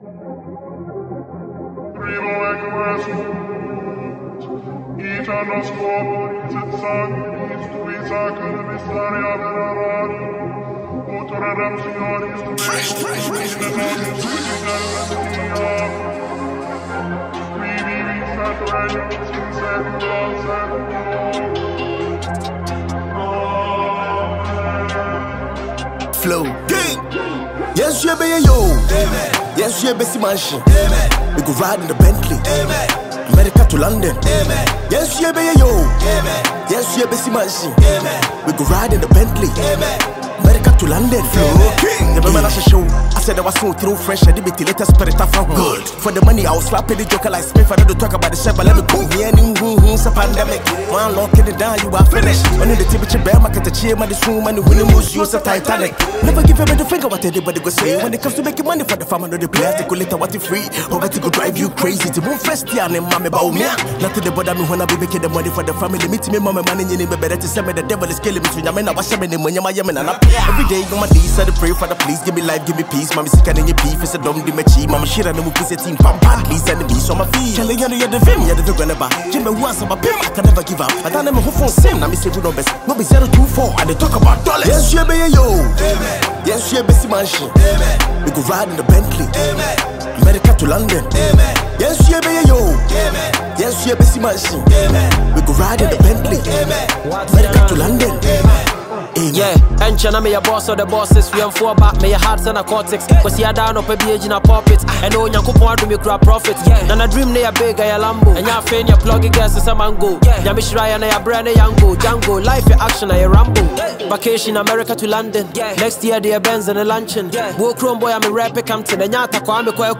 With each rapper delivering mind-blowing verses